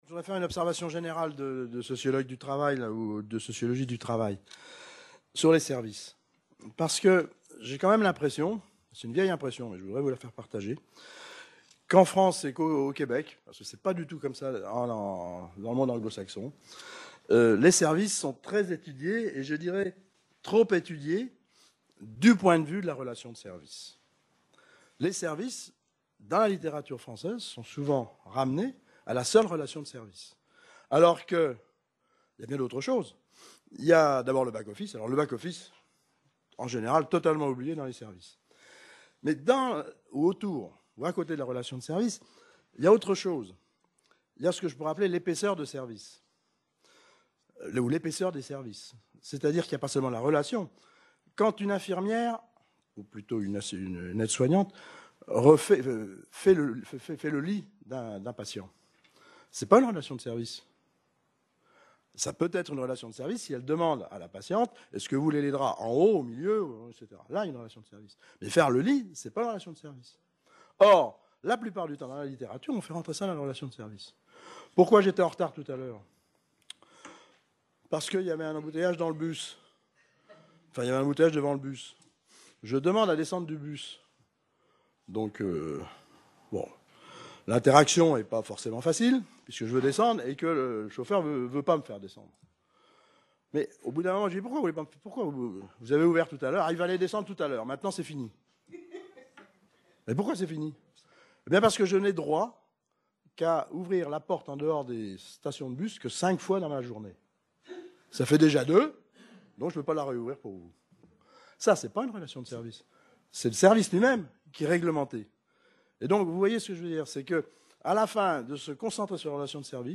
Syndicat / Conflit - Discussion | Canal U